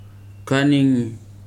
[kánɪ̀ŋỳ] v. walk